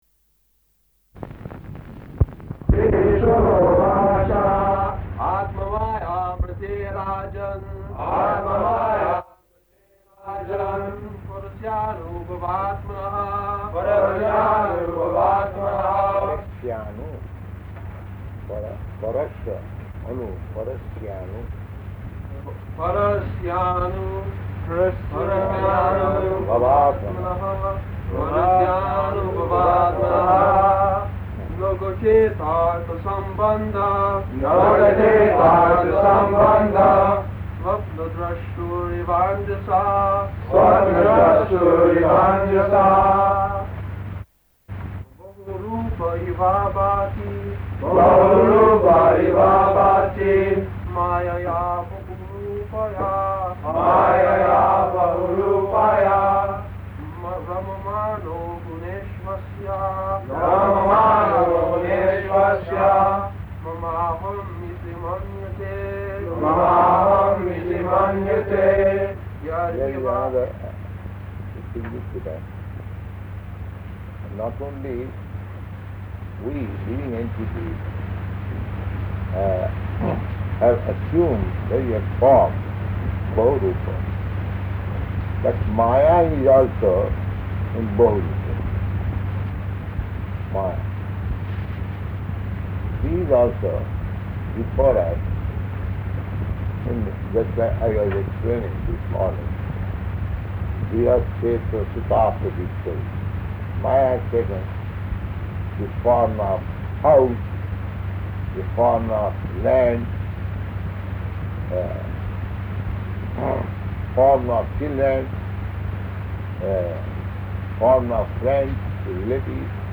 Type: Srimad-Bhagavatam
Location: Tokyo
[Poor Audio]